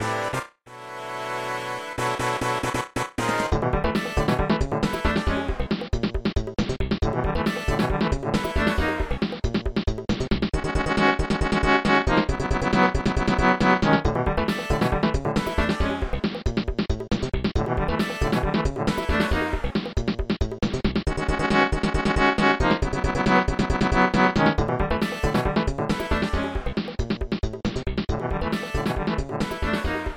Final battle theme